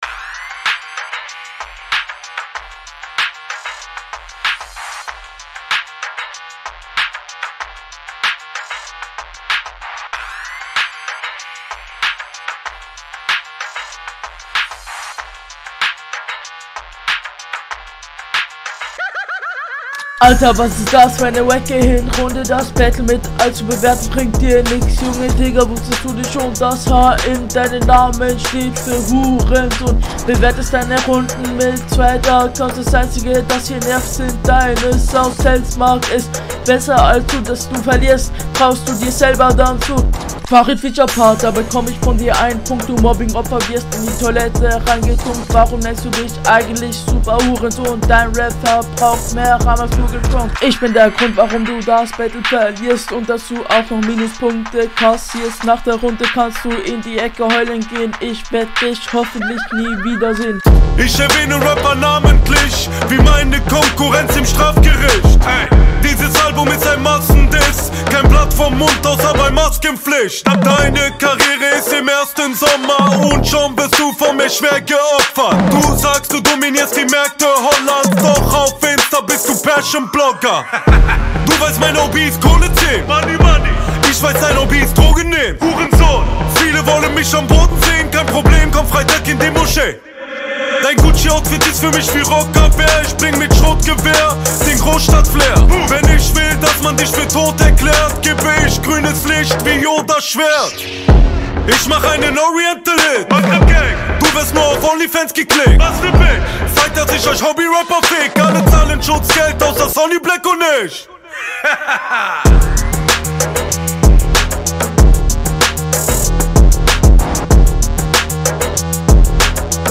Flow: bemüht. Klingt zwar auch danach, aber der takt wird des öfteren sowas wie getroffen …